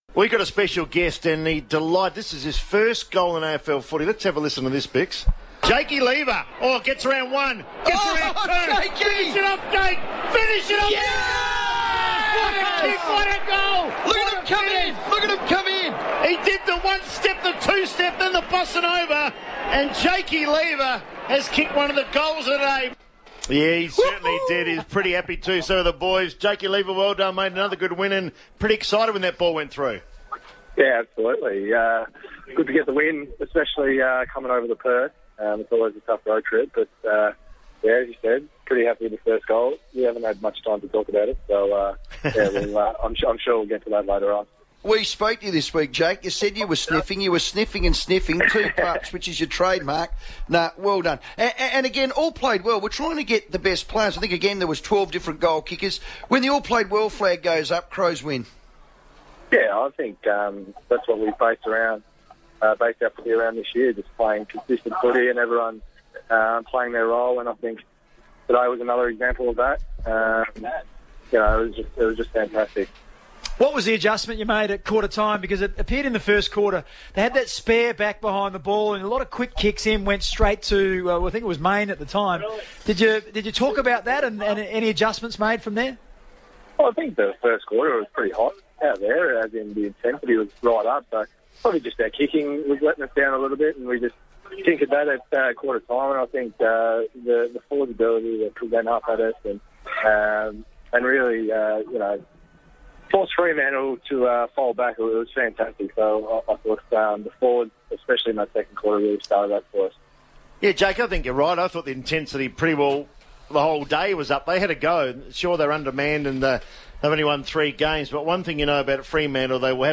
Crows defender Jake Lever talks on FIVEaa following Adelaide's 72-point win over the Dockers on Sunday